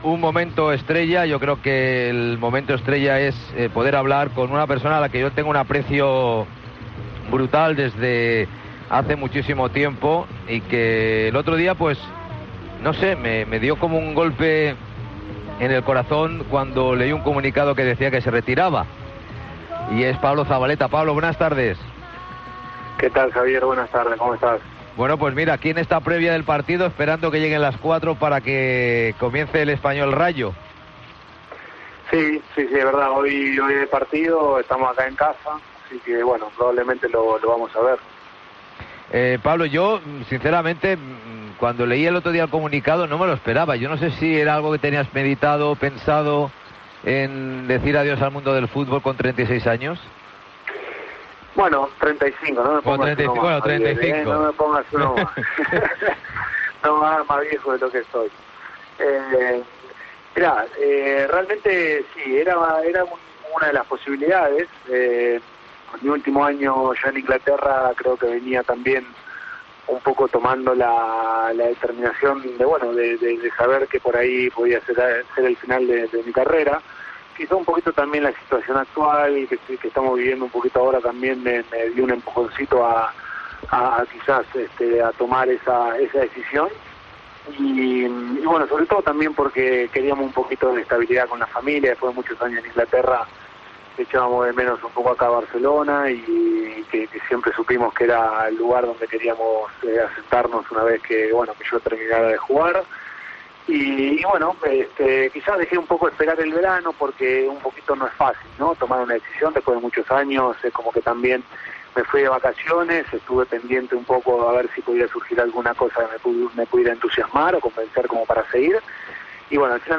Entrevista a Pablo Zabaleta